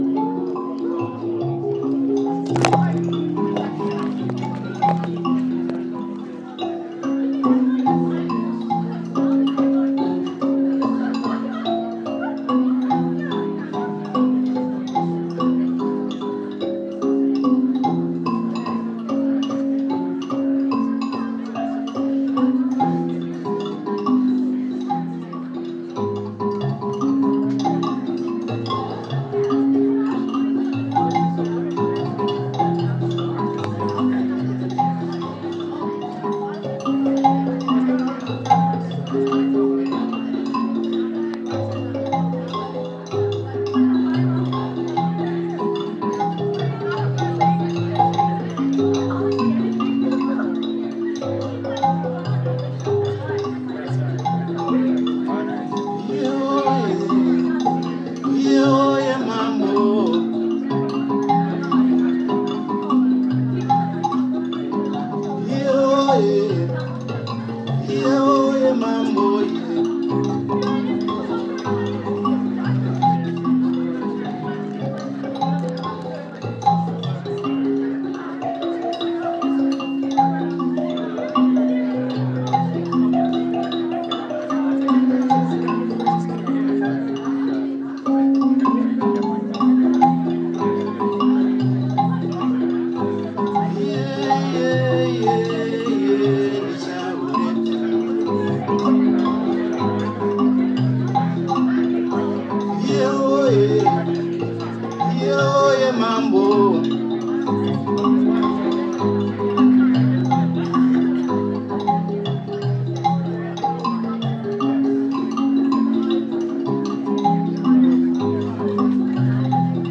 Demo of Zimbabwean thumb piano (Mbira) at soas